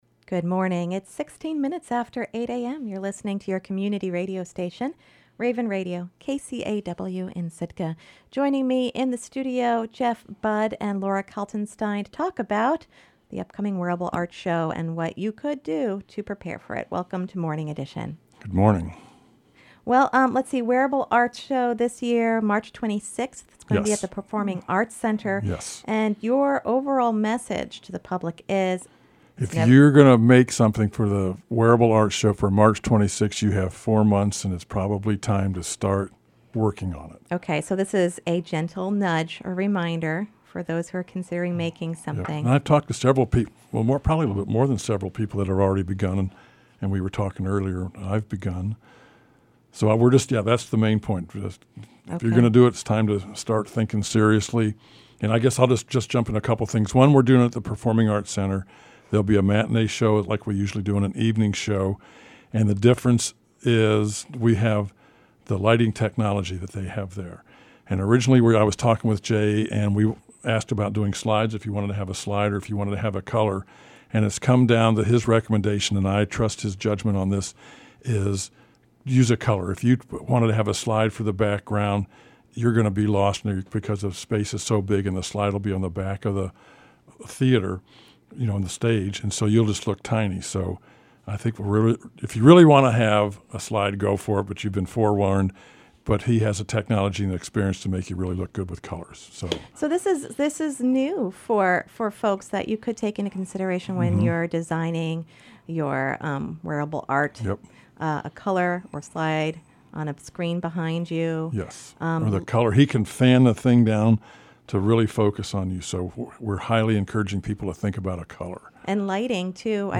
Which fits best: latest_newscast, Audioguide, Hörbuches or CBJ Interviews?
latest_newscast